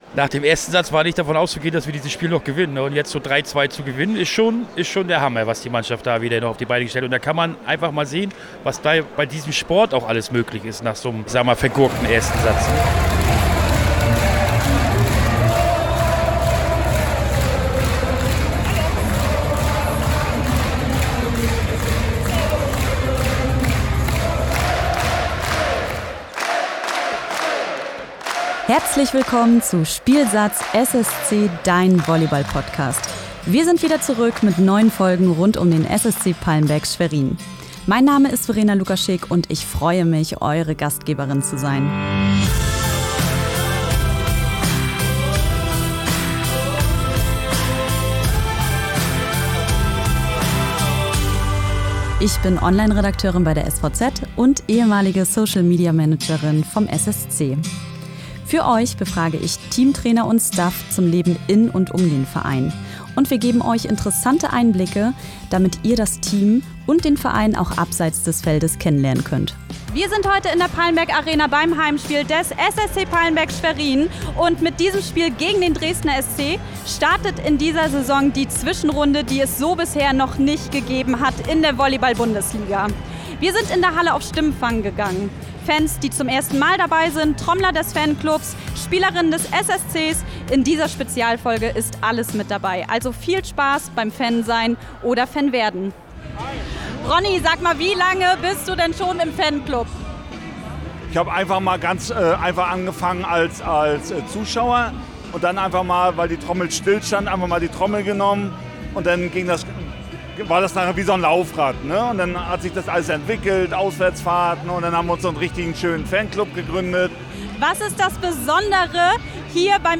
Wenn der SSC Palmberg Schwerin einen 0:2-Rückstand in einen 3:2-Sieg dreht, dann herrscht Ausnahmestimmung in der Schweriner Palmberg Arena. SSC-Fans erzählen, wie sie das Spiel gegen den Dresdner SC und die Atmosphäre in der Halle erlebt haben.